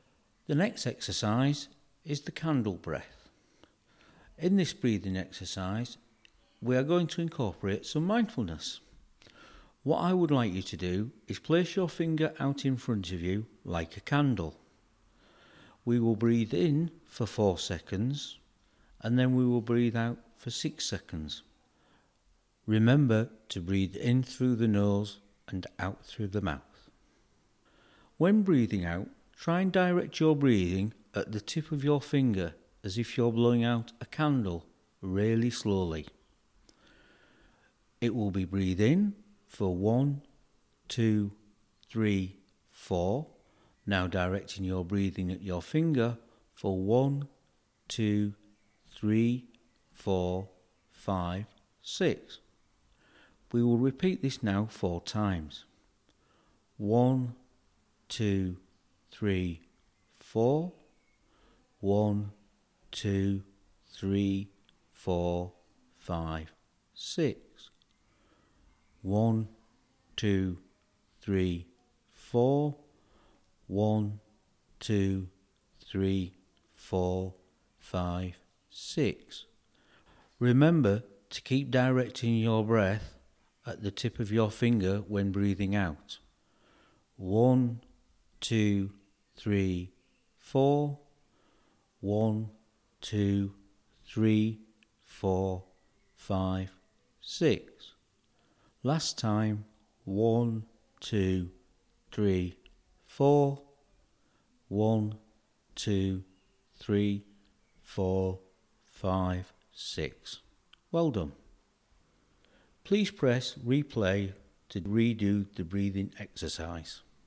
Whether you need to hear a calming voice or just something to distract you. These are our very own breathing exercises which hope to slow you down and take your mind elsewhere right now.